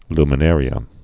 (lmə-nârē-ə)